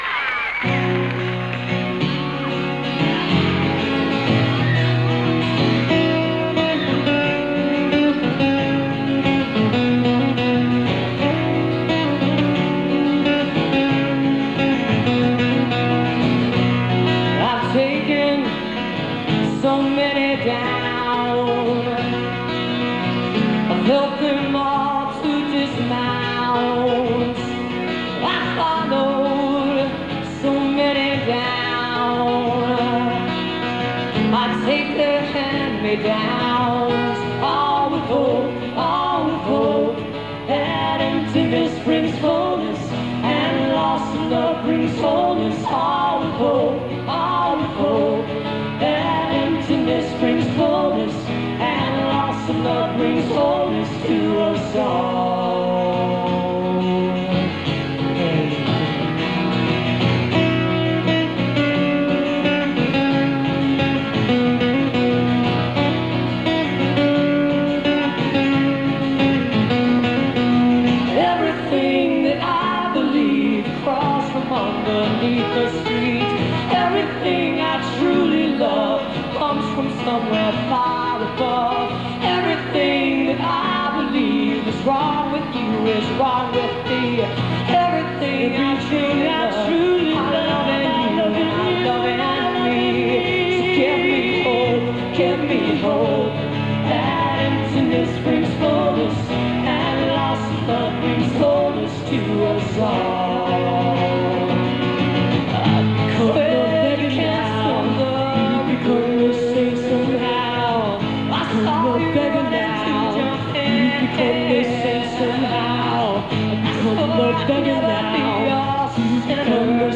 (acoustic duo show)
(radio broadcast)